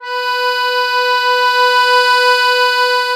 MUSETTE1.9SW.wav